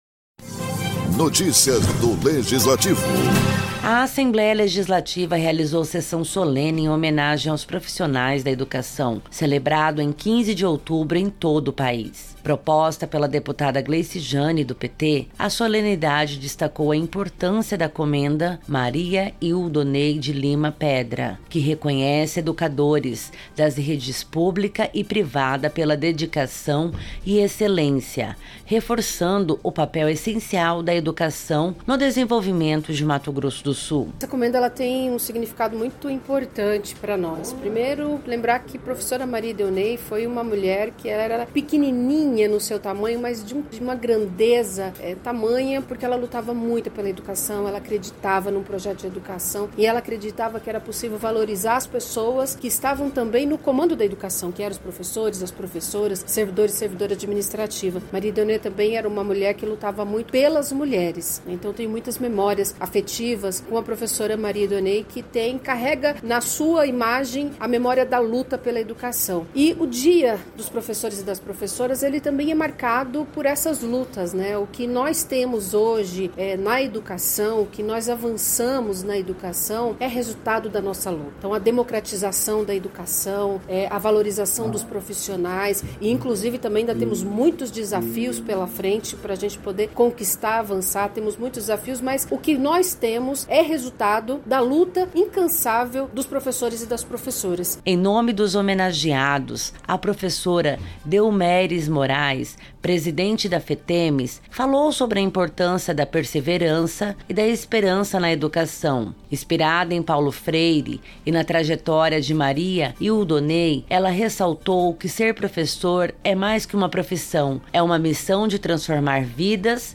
A Assembleia Legislativa realizou sessão solene em homenagem aos profissionais da educação, celebrado em 15 de outubro em todo o país.
Produção e Locução